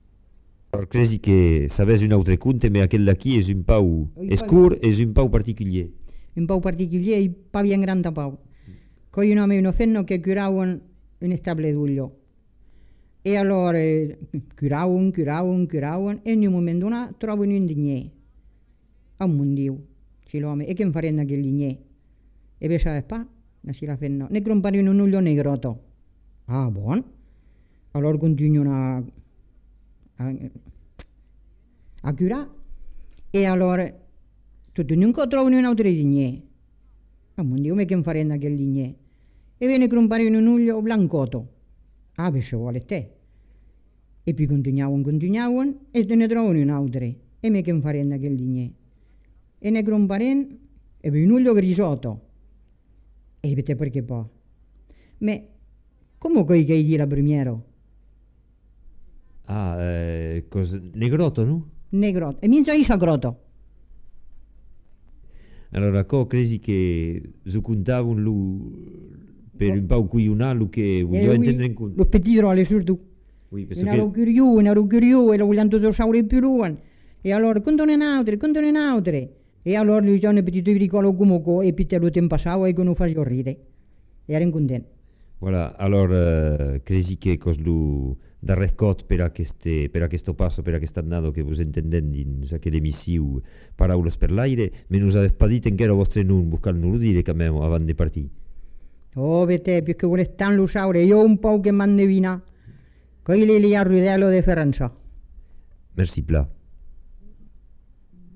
Aire culturelle : Haut-Agenais
Genre : conte-légende-récit
Effectif : 1
Type de voix : voix de femme
Production du son : parlé
Classification : contes-attrape